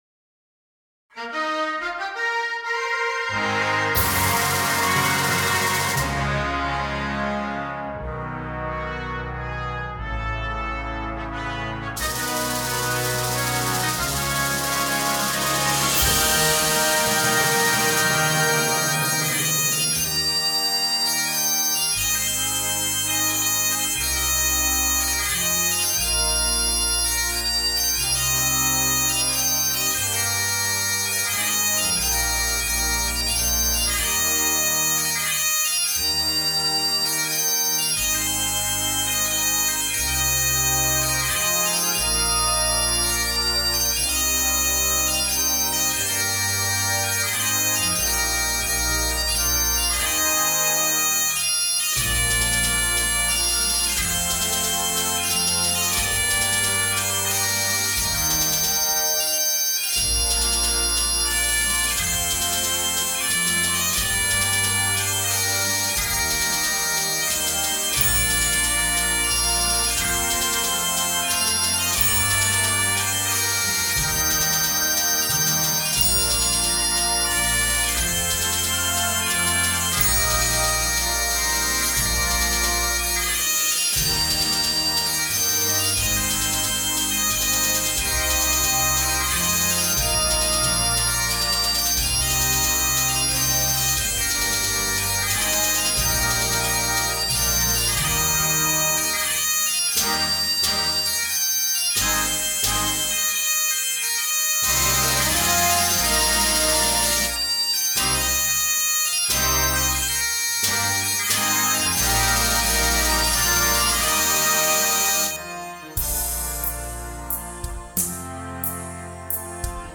Timpani
Drum Kit
Glockenspiel